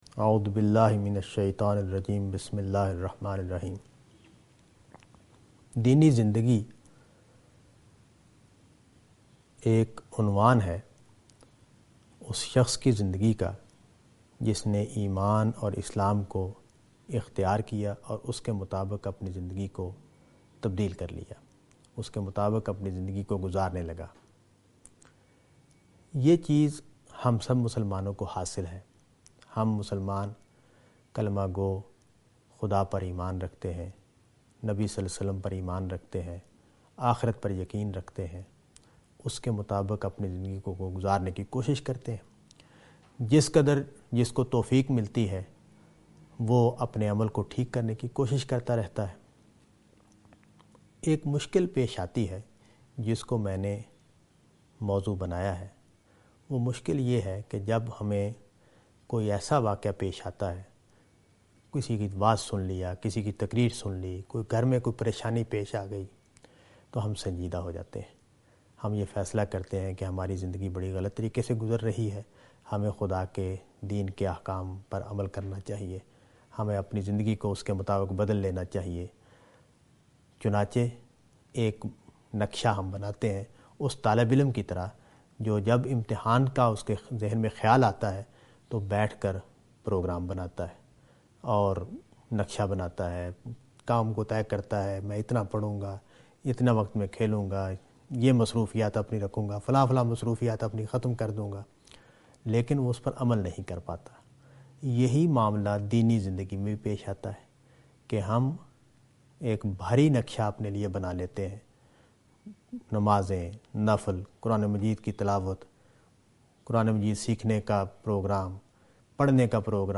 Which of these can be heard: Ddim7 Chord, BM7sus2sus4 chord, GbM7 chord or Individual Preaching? Individual Preaching